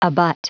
Prononciation du mot abut en anglais (fichier audio)
Prononciation du mot : abut